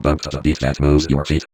VVE1 Vocoder Phrases 04.wav